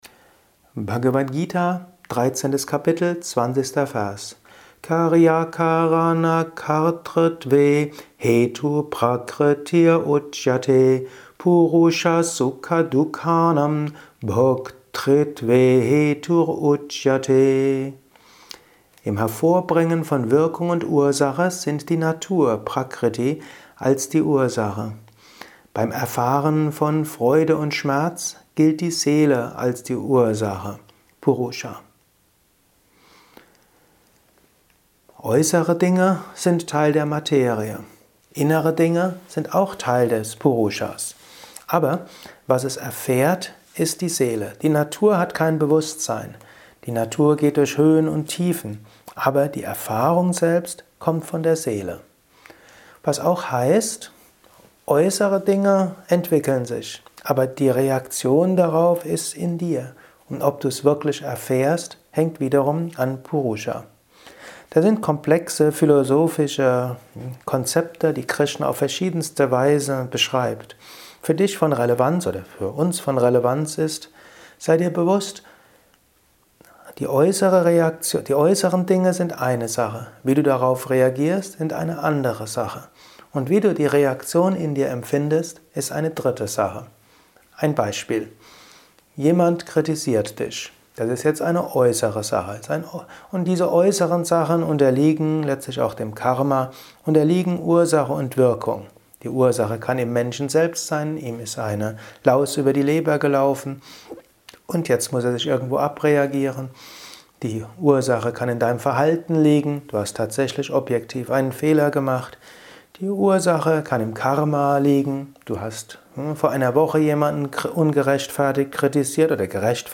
Dies ist ein kurzer Kommentar als Inspiration für den heutigen